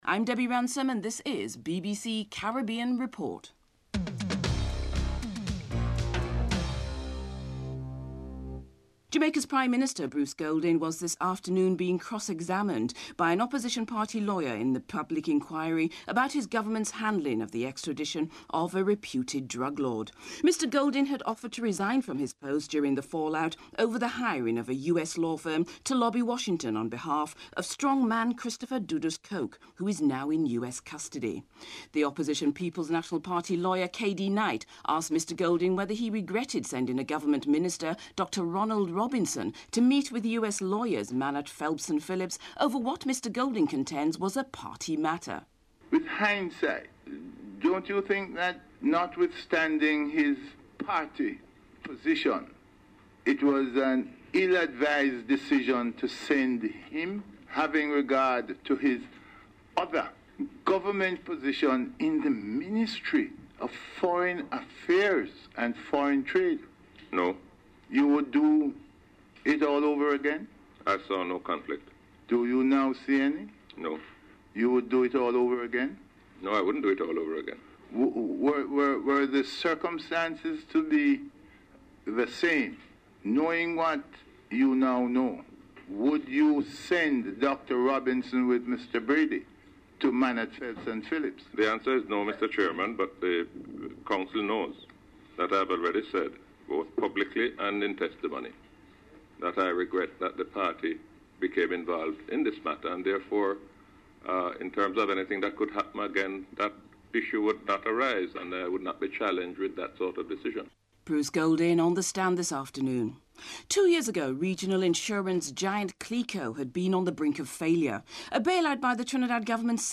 This penultimate program in the BBC Caribbean Report series deals mainly with economic and social issues facing Caribbean countries, as well as the implications of some economic, social and legal developments in Britain as they relate to Caribbean diasporic communities. The program concludes with an archives segment, which features snippets of previous broadcasts spanning a period from as far back as world war II to as recent as 2010, and highlighting significant personalities and events in Caribbean history.